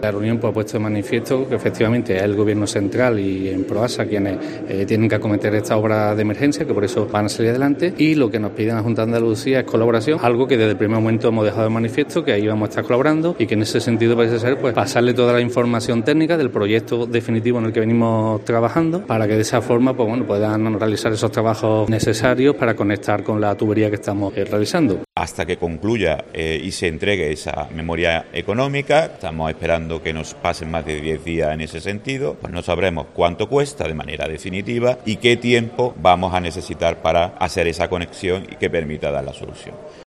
Escucha al delegado de Agricultura en la Junta, Juan Ramón Pérez; y al diputado provincial, Esteban Morales